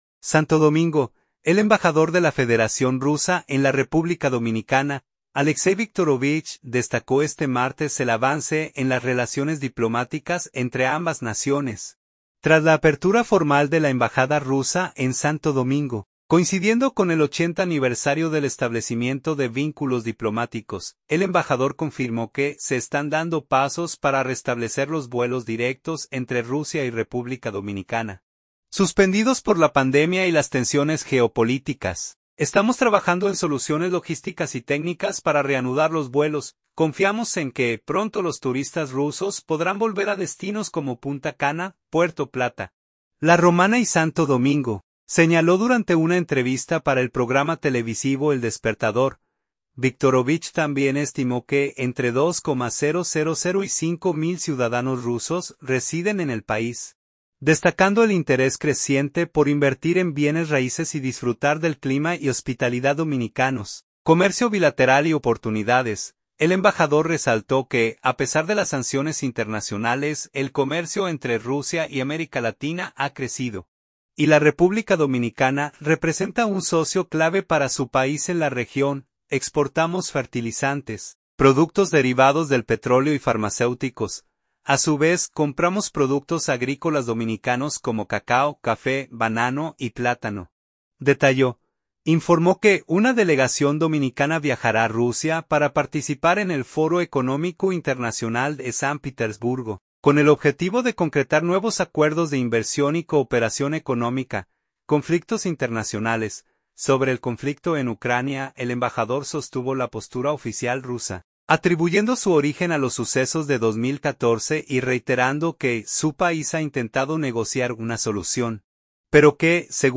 “Estamos trabajando en soluciones logísticas y técnicas para reanudar los vuelos. Confiamos en que pronto los turistas rusos podrán volver a destinos como Punta Cana, Puerto Plata, La Romana y Santo Domingo”, señaló durante una entrevista para el programa televisivo El Despertador.